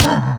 1.21.5 / assets / minecraft / sounds / mob / camel / hurt1.ogg
hurt1.ogg